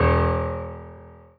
piano-ff-08.wav